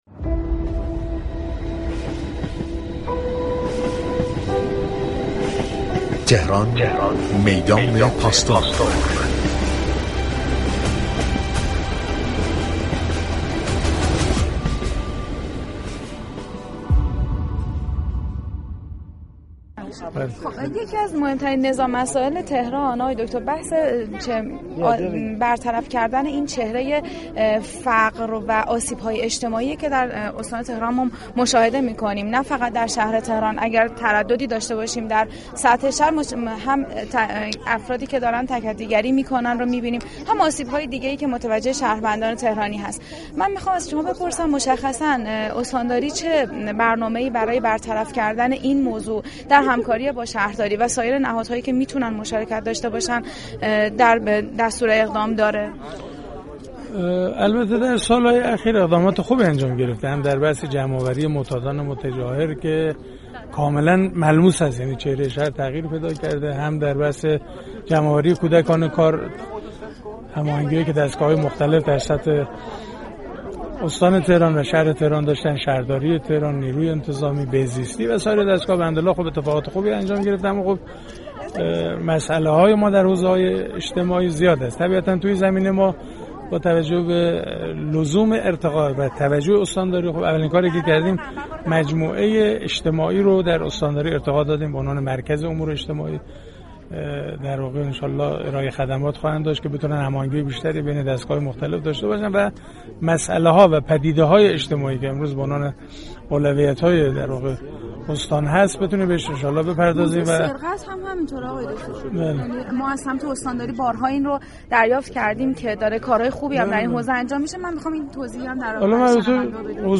استاندار تهران در گفتگو با خبرنگار رادیو تهران از حل معضل تكدی‌گری در پایتخت خبر داد و تاكید كرد: این موضوع چهره شهر را دگرگون كرده است.